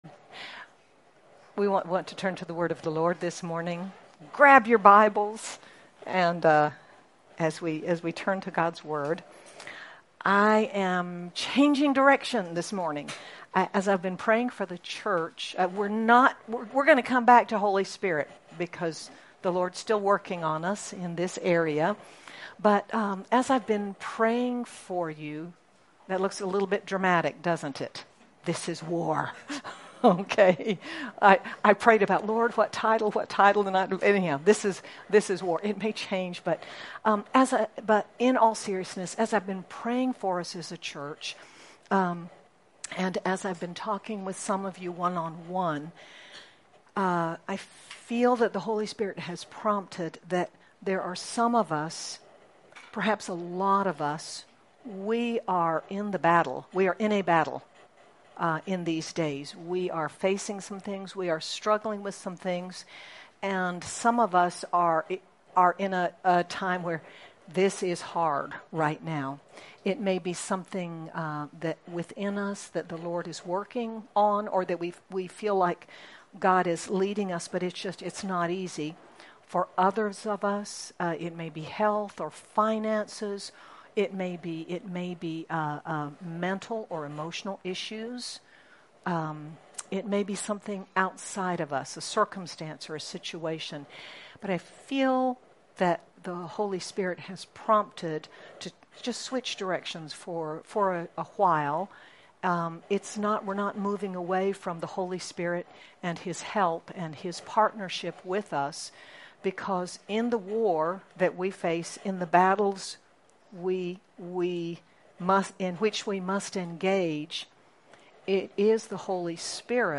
We begin a series on spiritual warfare with this message. Sermon by